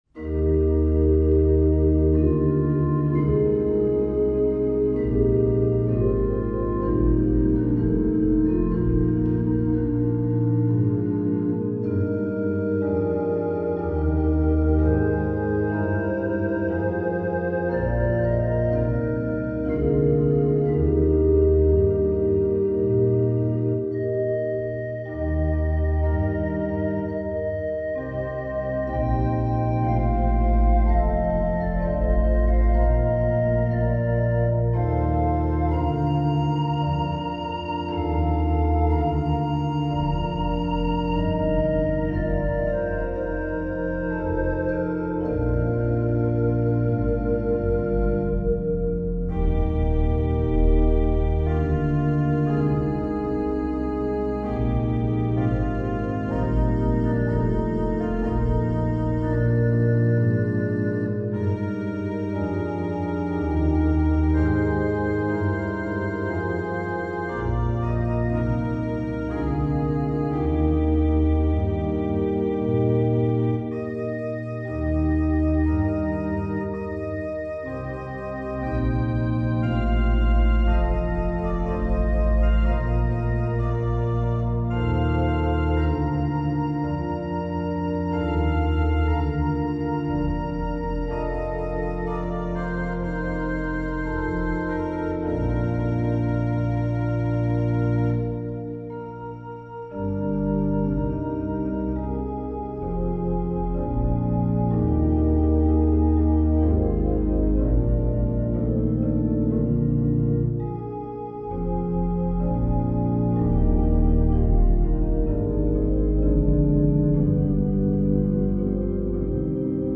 Countless arrangements have been made in various keys and for a wide range of instruments – including now, this organ arrangement.
It’s a beautiful melody, which deserves a gentle registration. Consider a mild solo stop, such as a soft reed, or the combination of flutes 8, 4, and 3.
Accompany this with an 8’ flute on another manual, optionally adding a 4’ flute.
The first 16 bars are repeated – perhaps with a different registration.